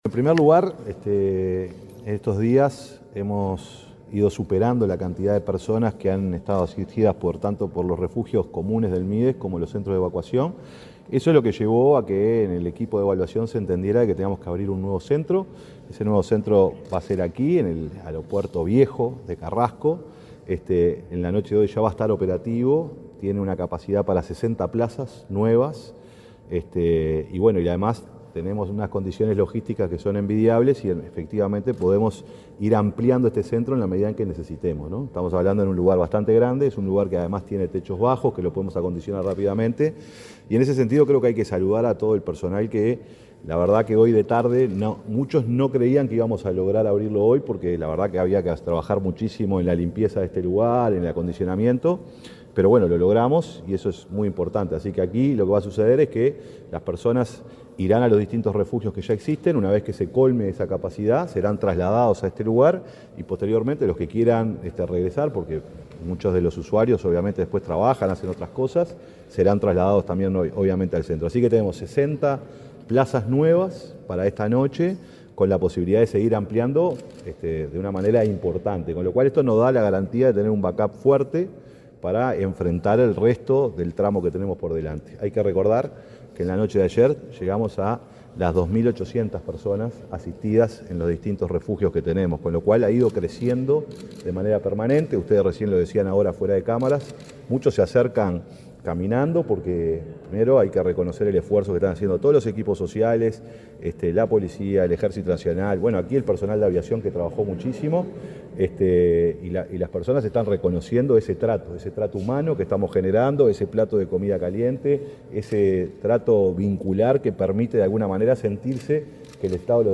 Declaraciones del secretario de Presidencia, Alejandro Sánchez
Declaraciones del secretario de Presidencia, Alejandro Sánchez 01/07/2025 Compartir Facebook X Copiar enlace WhatsApp LinkedIn El secretario de la Presidencia, Alejandro Sánchez, dialogó con la prensa durante su visita al nuevo centro de evacuación para personas en situación de calle, ubicado en el antiguo edificio del aeropuerto internacional de Carrasco.